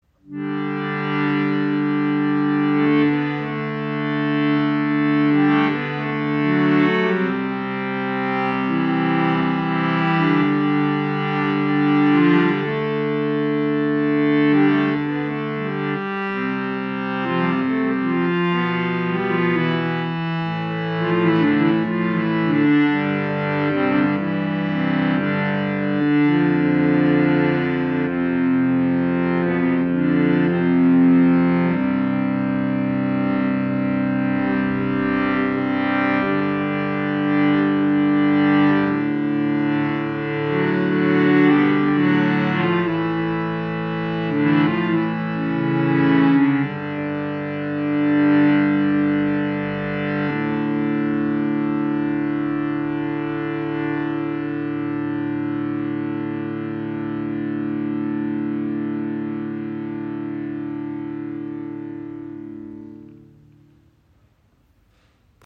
Harmonium Paloma Compactina | 3¼ Oktaven
So lässt sich eine der beiden Stahlfedern aushängen und das Harmonium kann so mit weniger Druck, und damit auch leiser gespielt werden.
Stimmung: 440 Hz.